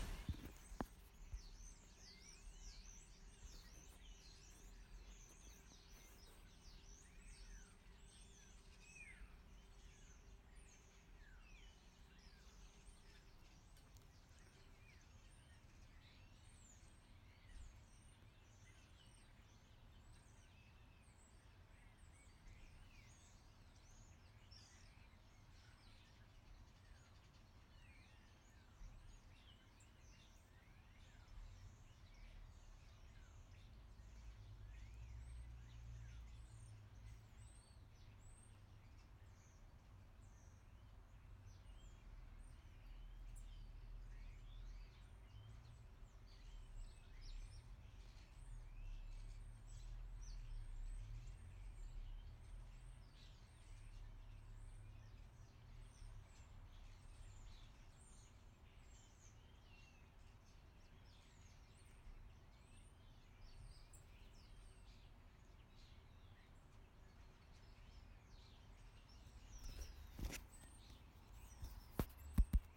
Winter birds, Saturday 7 January 2017